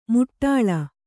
♪ muṭṭāḷa